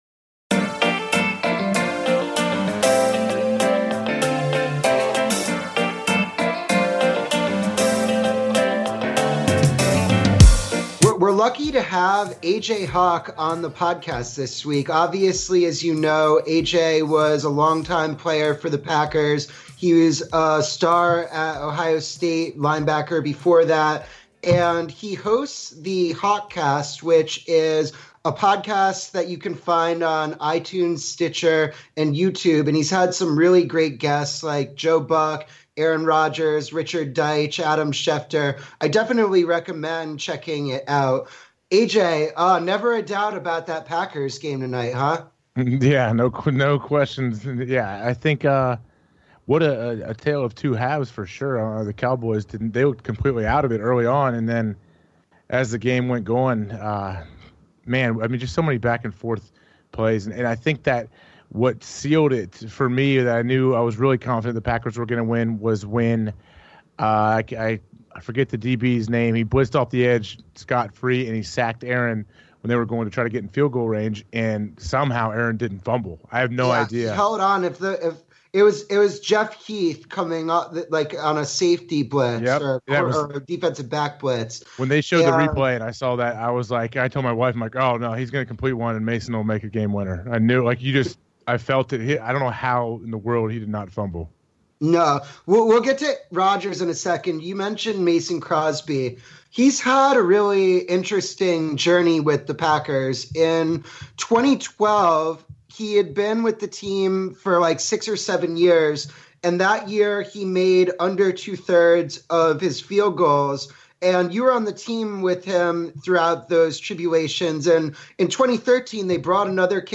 A conversation with the former Packers linebacker.